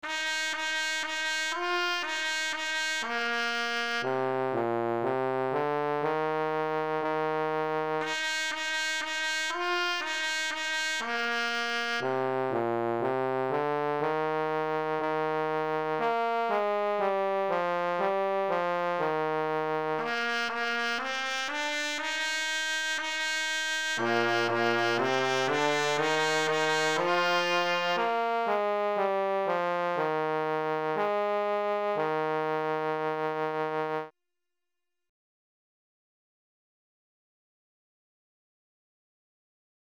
Traditional melody